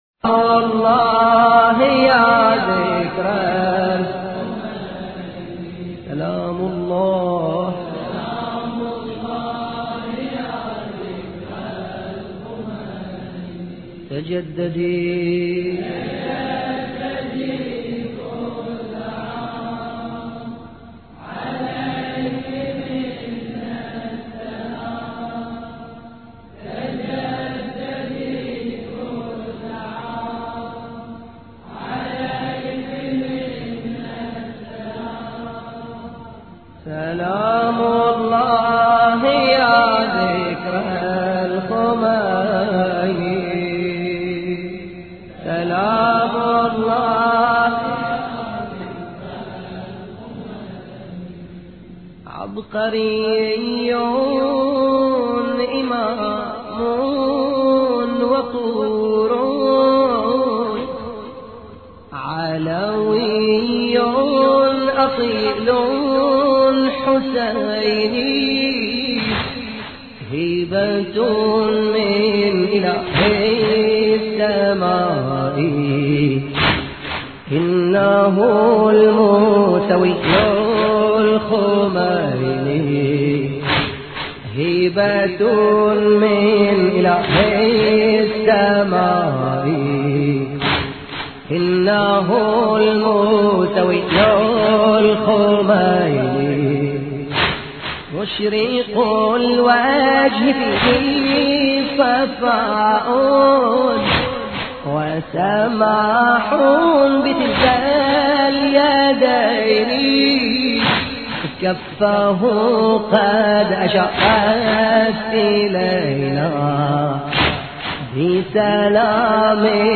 مراثي الامام الخميني (ره)